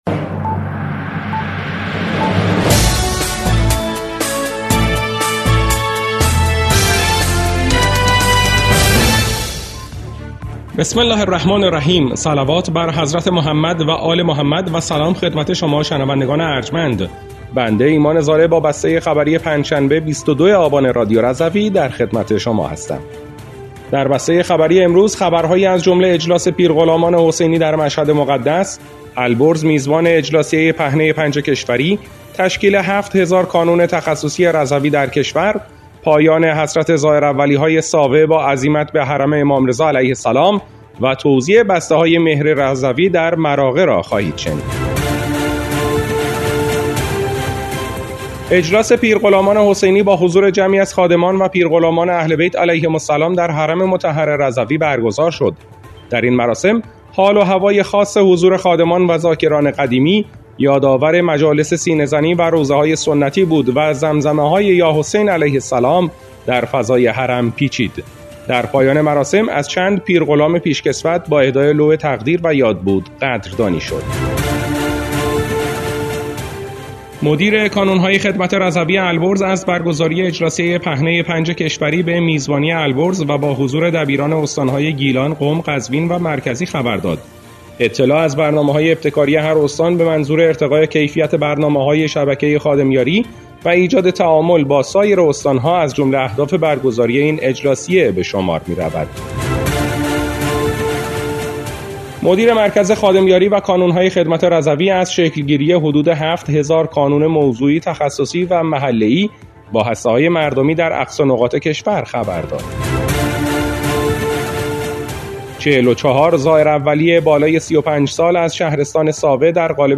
بسته خبری ۲۲ آبان ۱۴۰۴ رادیو رضوی؛